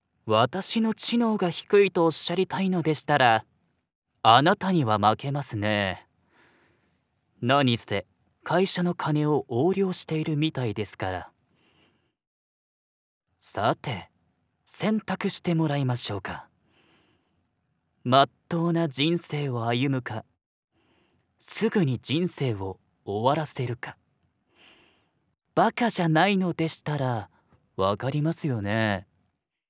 ボイスサンプル
新米熱血刑事
ストーカー気質な男
エリート頭脳派刑事
冒険者ギルドのリーダー
ナレーション（企業VP）
ナレーション（地方自治体向け）